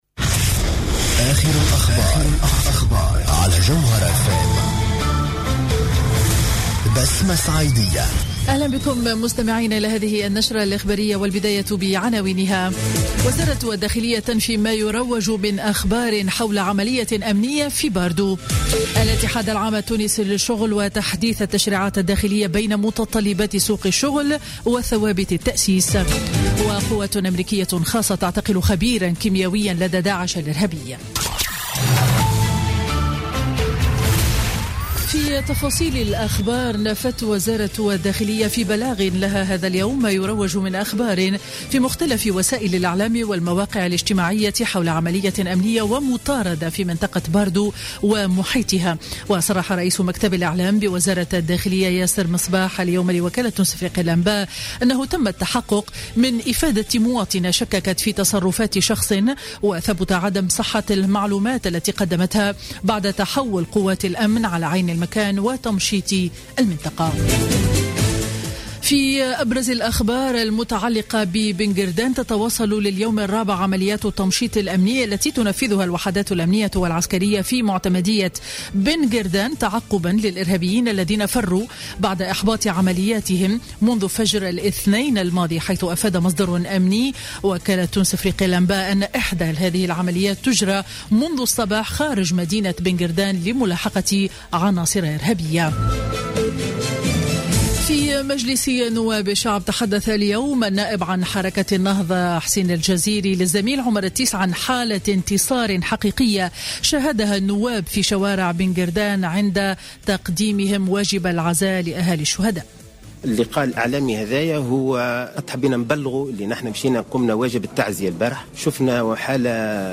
نشرة أخبار منتصف النهار ليوم الخميس 10 مارس 2016